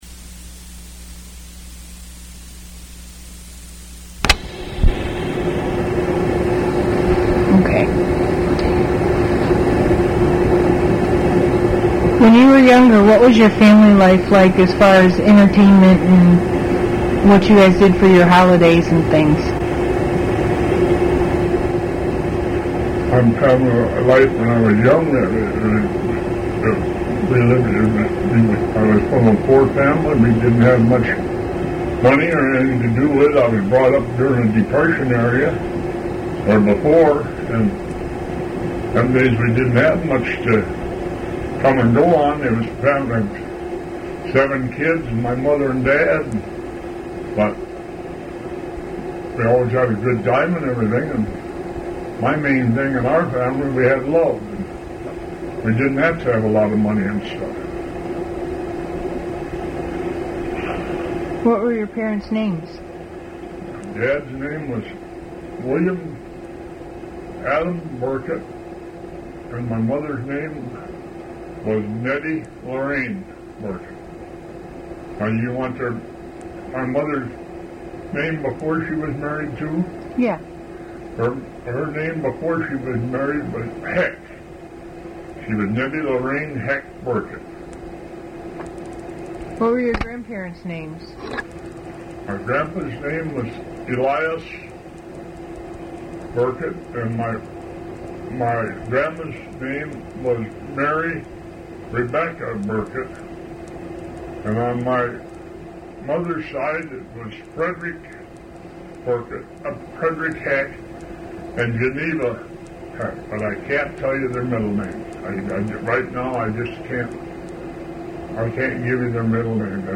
Oral History
Interviews